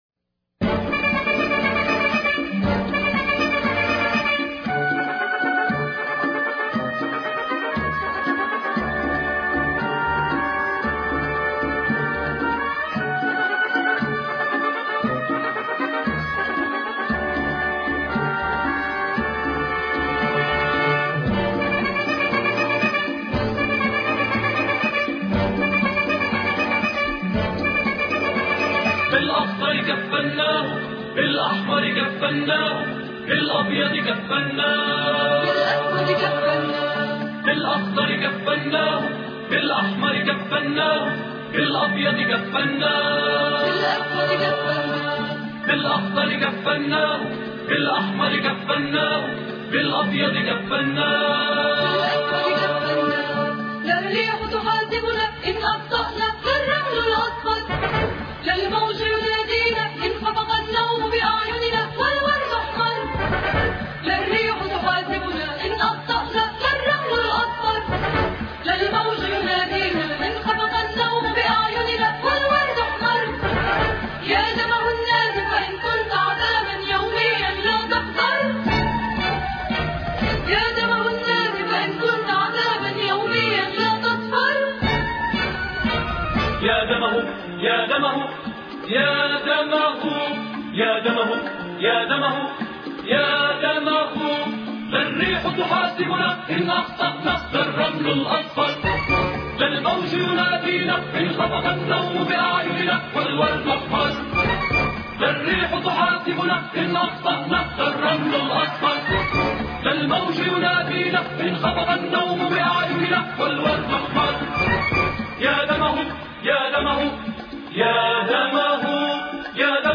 الحماسية